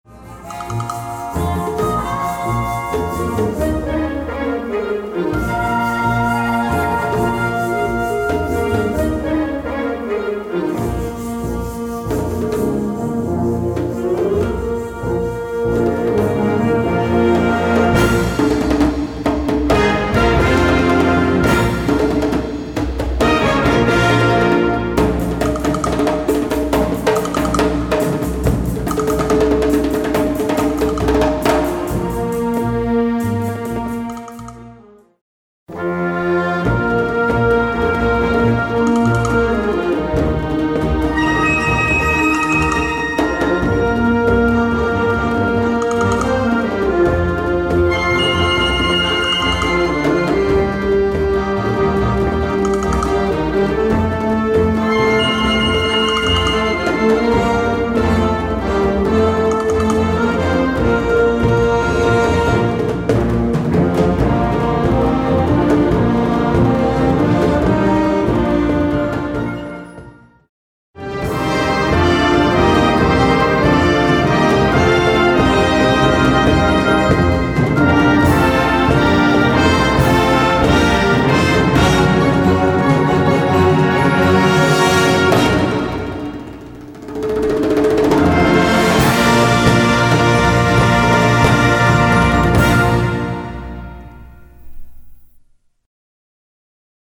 Gattung: Savannah-Impression
Besetzung: Blasorchester
Das Lied eines exotischen Vogels erklingt...